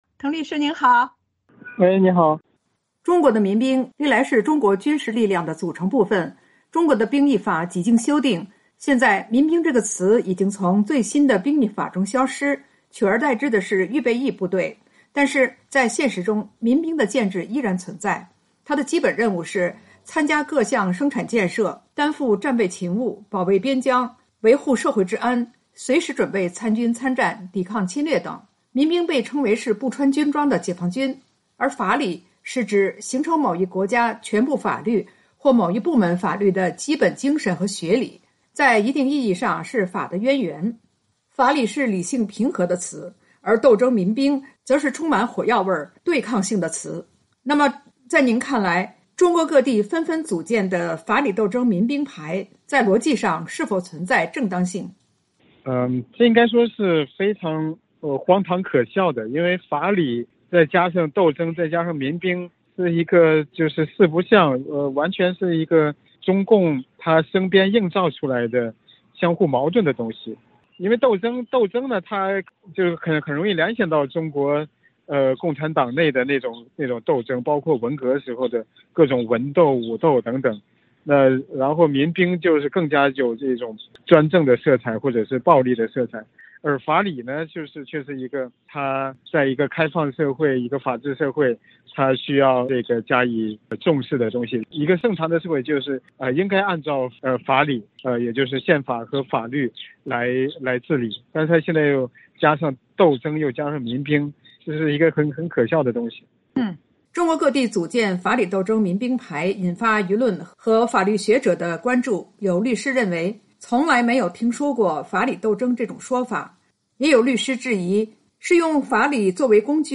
VOA连线：中国各地组建“法理斗争民兵排” 滕彪：完全是中共生编硬造相互矛盾的东西